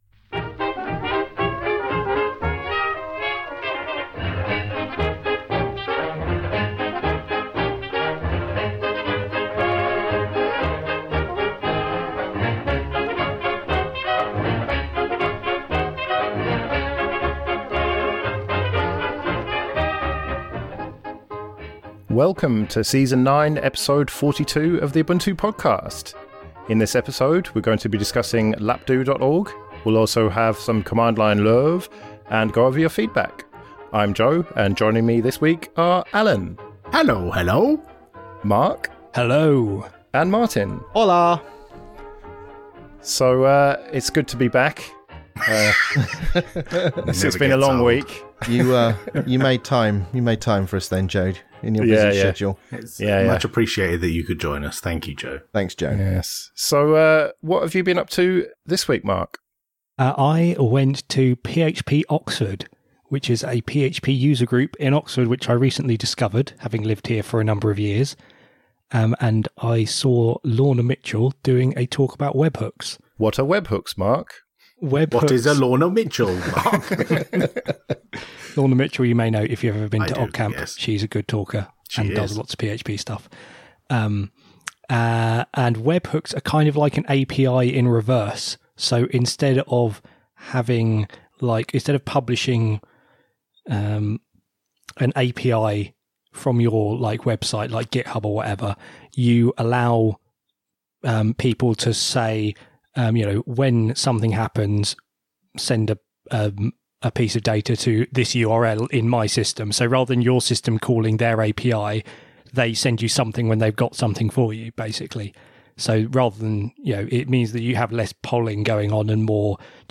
The same line up as last week are here again for another episode.